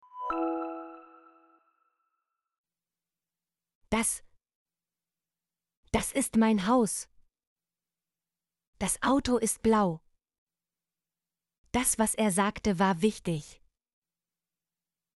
das - Example Sentences & Pronunciation, German Frequency List